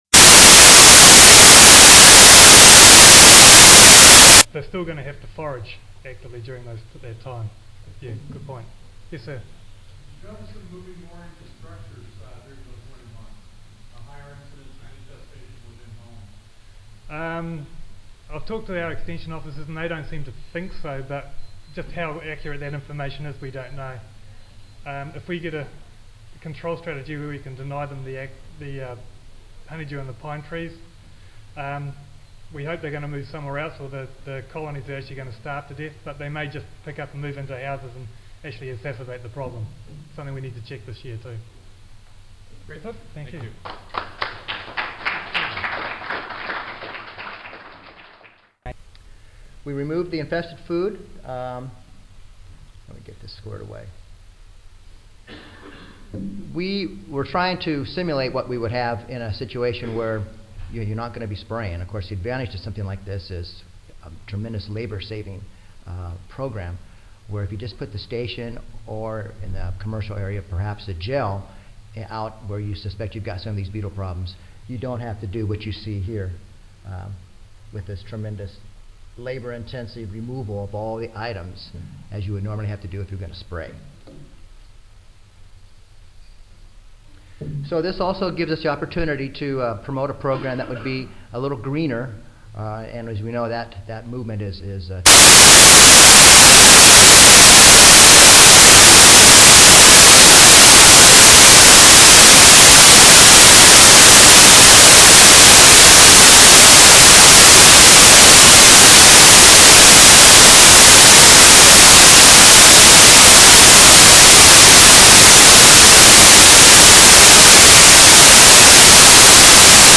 8:40 AM Recorded presentation Audio File 0070 Phylogenetic characterization of Wolbachia symbionts infecting Cimex lectularius L. and Oeciacus vicarius Horvath (Hemiptera: Cimicidae)